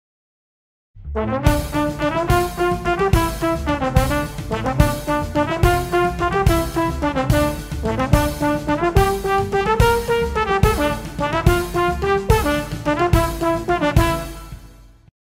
Звуки тромбона
Музыкальная пауза